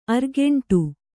♪ argeṇṭu